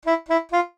jingles-saxophone_12.ogg